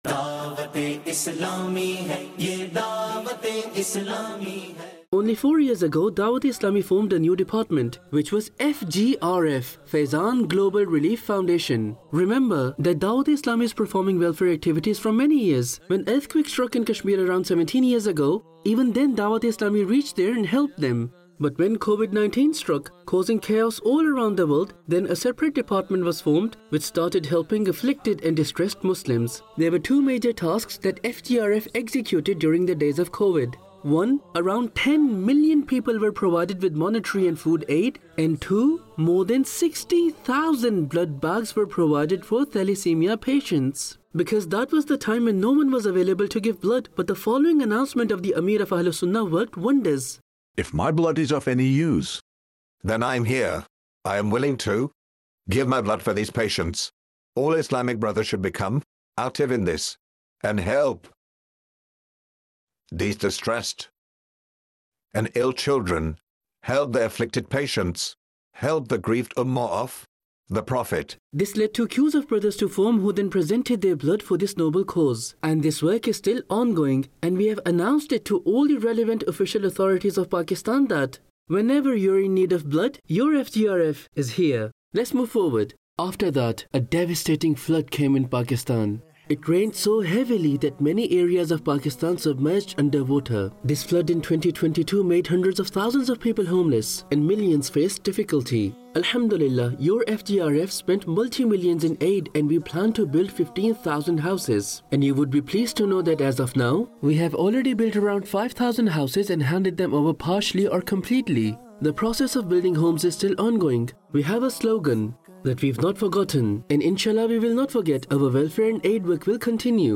khutba
FGRF | Department of Dawateislami | Documentary 2024 | English Dubbed